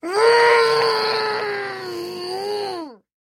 Звуки злости, ворчання
Звук недовольства происходящим